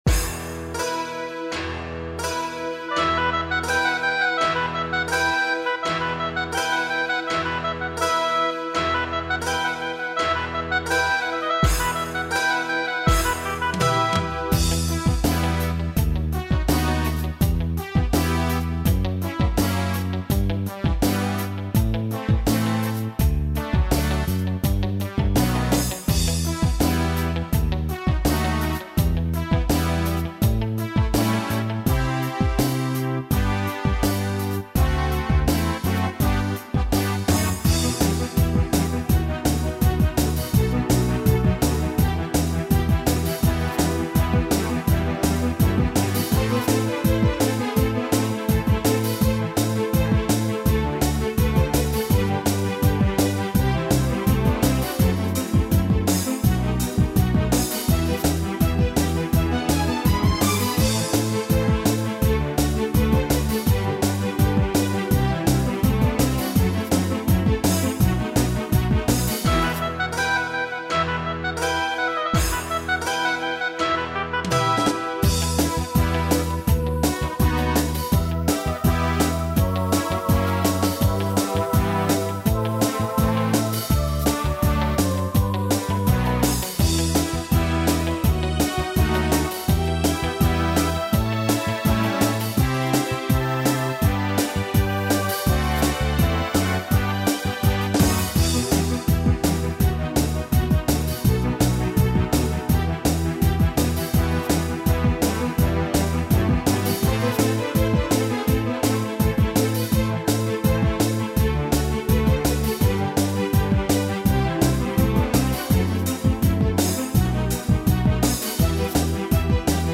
минусовка версия 239859